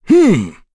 Dakaris-Vox_Attack4.wav